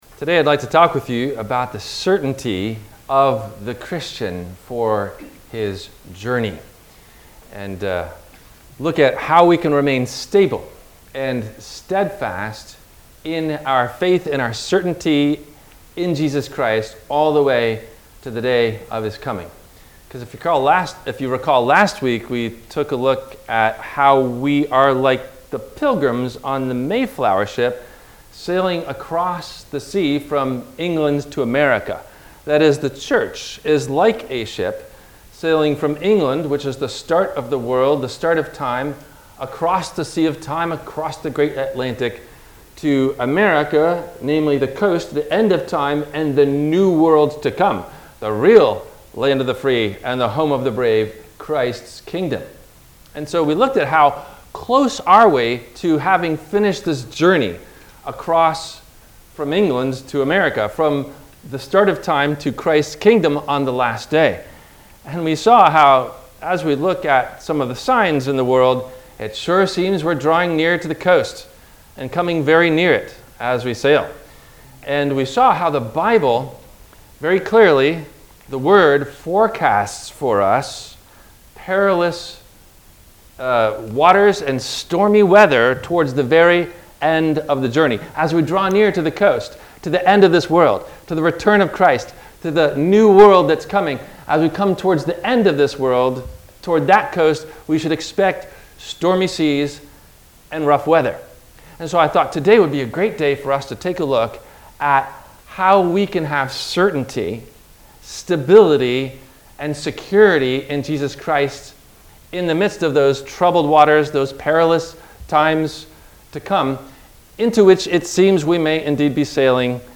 No Questions asked before the Sermon message:
WMIE Radio – Christ Lutheran Church, Cape Canaveral on Mondays from 12:30 – 1:00